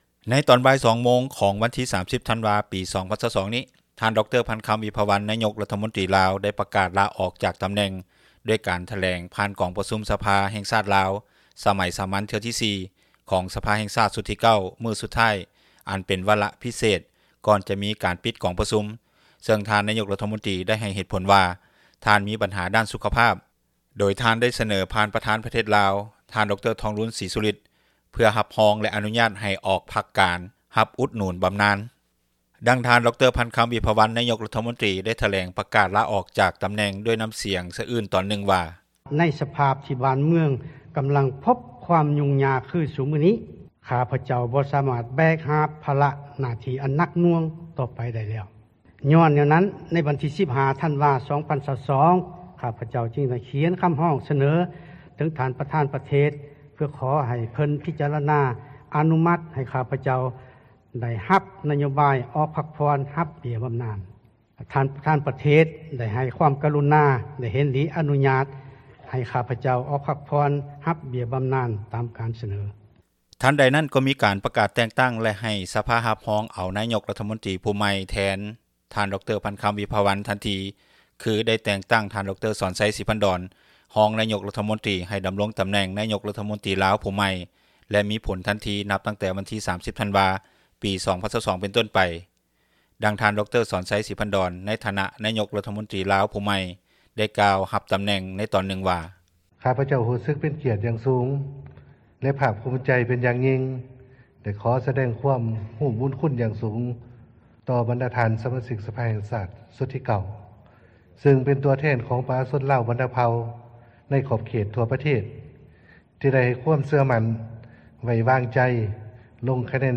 ດັ່ງທ່ານ ດຣ. ພັນຄຳ ວິພາວັນ, ນາຍົກຣັຖມົນຕຣີ ໄດ້ຖແລງ ປະກາດລາອອກ ຈາກຕຳແໜ່ງ ດ້ວຍນ້ຳສຽງສະອື້ນ ຕອນນຶ່ງວ່າ: